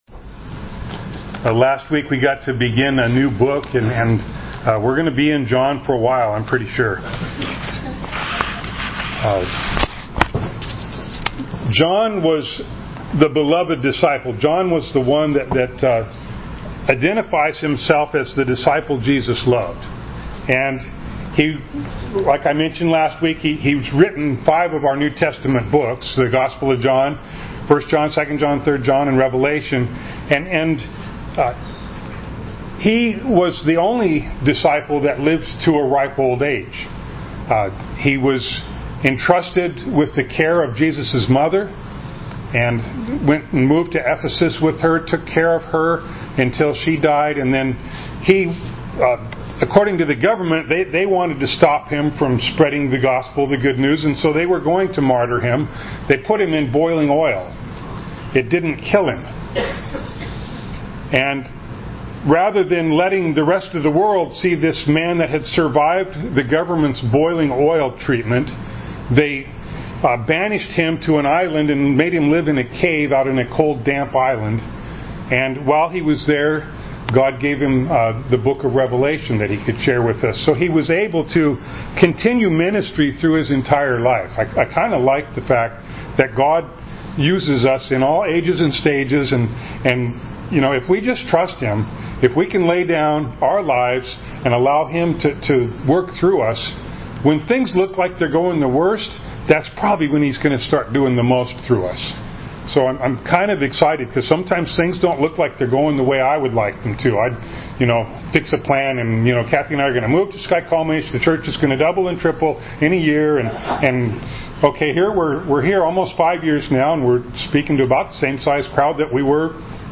John 1:6-13 Service Type: Sunday Morning Bible Text